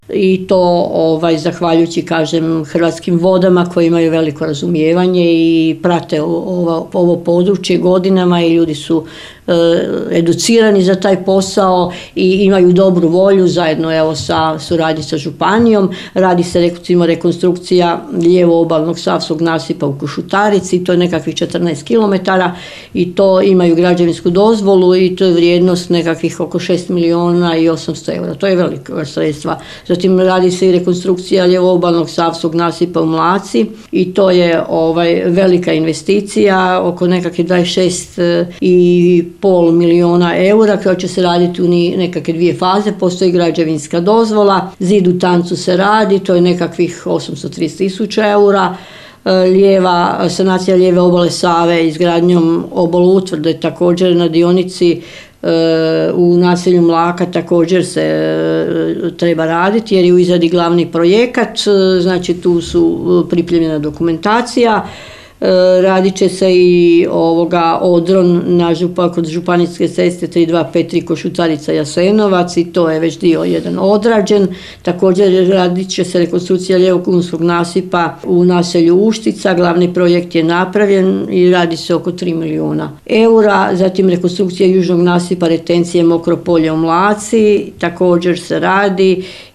Načelnica navodi o kojim je točno investicijama riječ